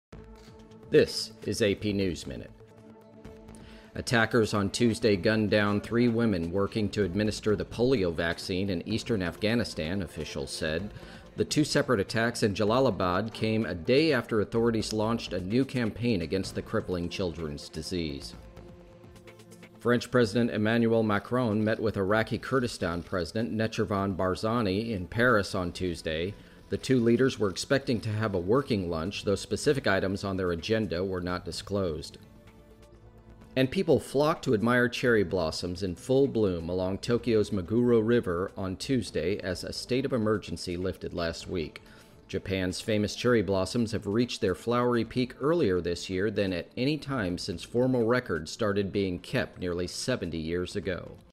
美语听力练习素材:紧急状态下日本迎最早樱花季|美语听力练习素材
News